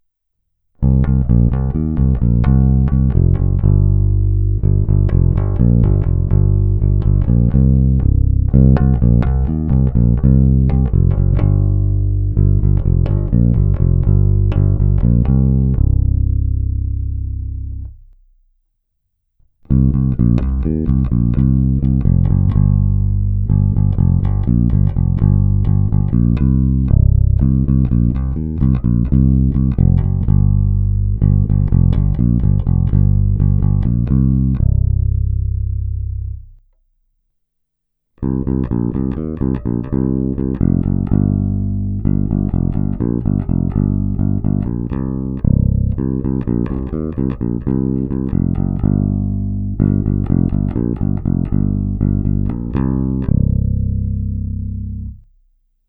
Není-li uvedeno jinak, následující nahrávky jsou provedeny rovnou do zvukové karty, v pasívním režimu a s plně otevřenou tónovou clonou.